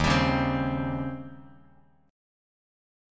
D#13 chord